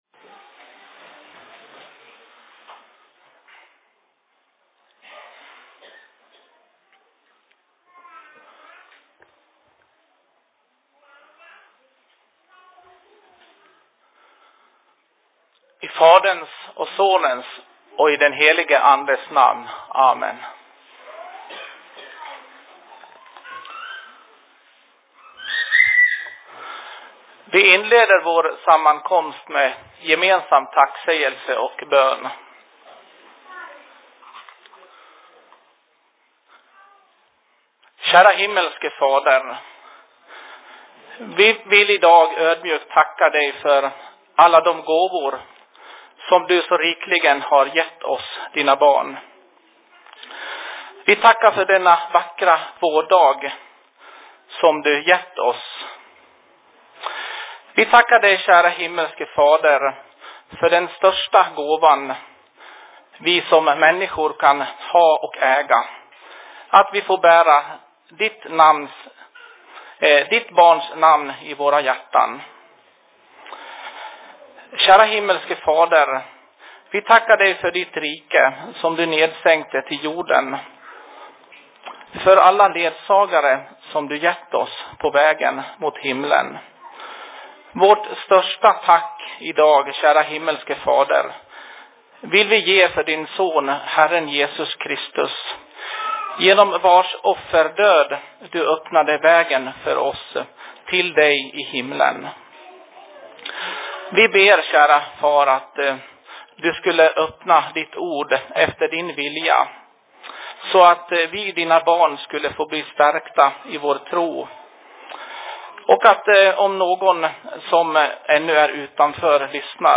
Se Predikan I Dalarnas Fridsförening 06.04.2014
Plats: SFC Dalarna